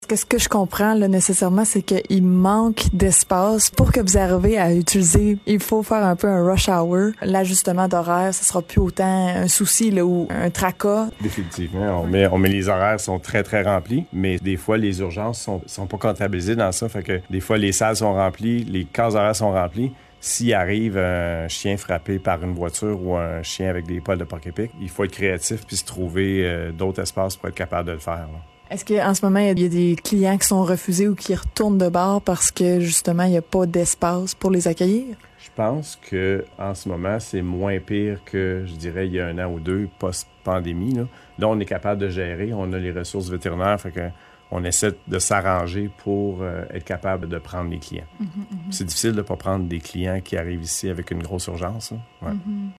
ENTREVUE-2.2.3-CLINIQUE-VET_01.mp3